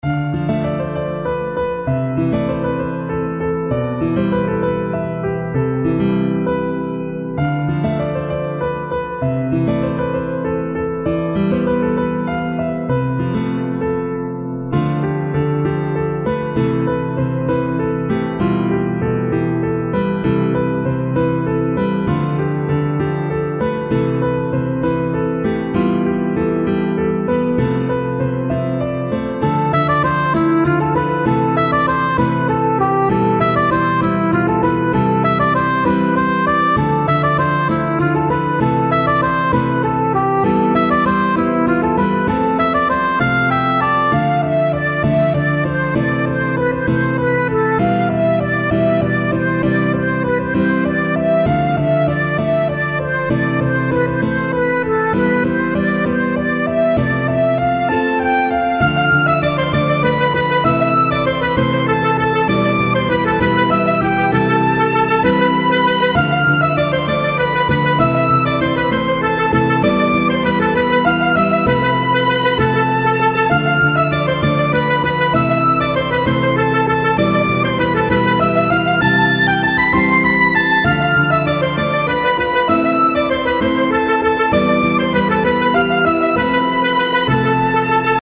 それぞれ１ループの音源です♪
イントロあり